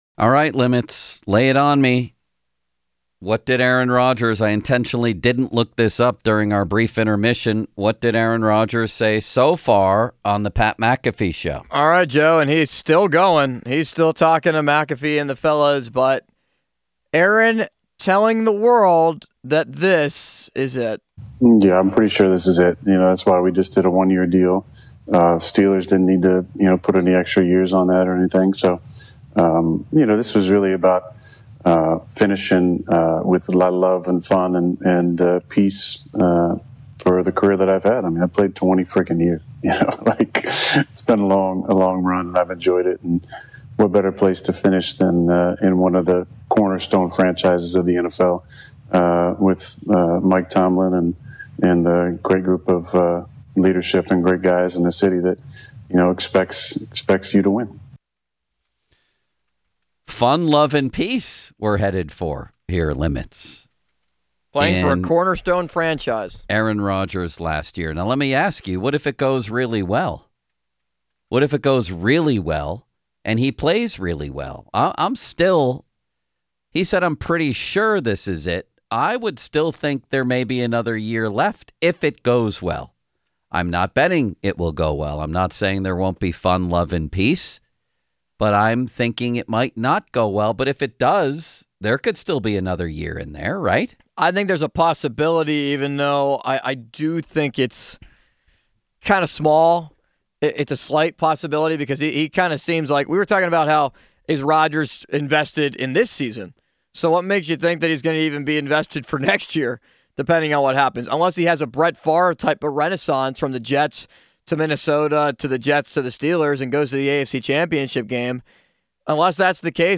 LIVE from the Amelie Charity Home, Mount Barker